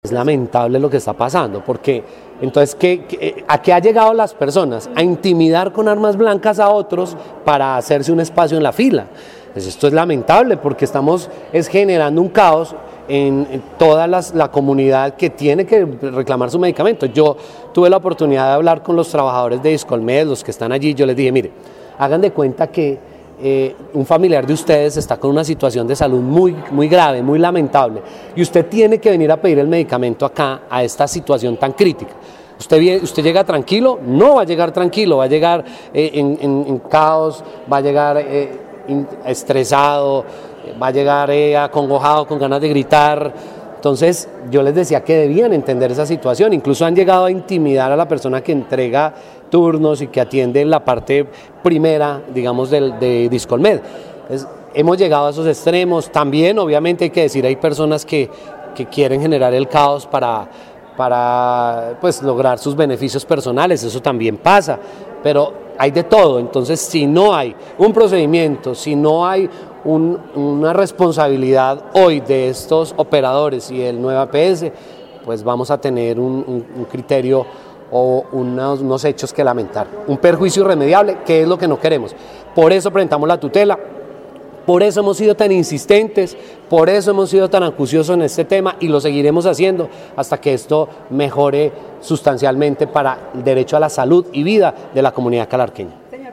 Personero de Calarcá, Jorge Triana